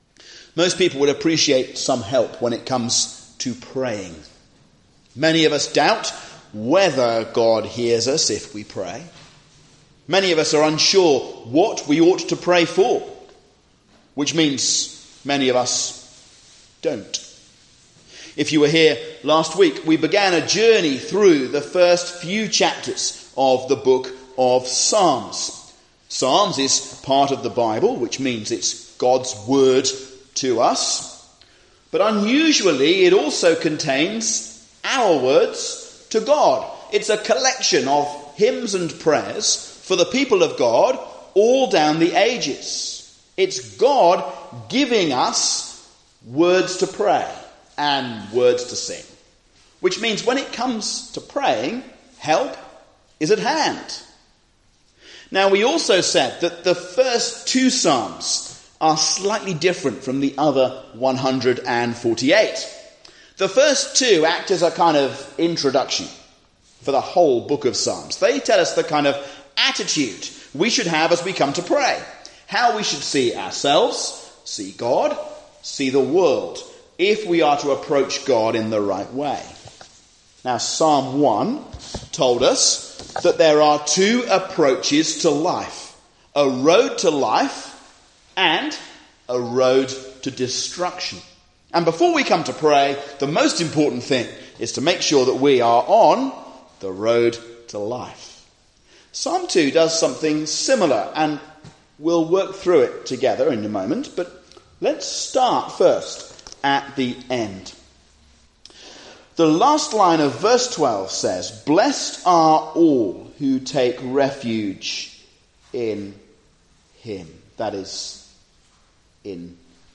The Path to Blessing, A sermon on Psalm 2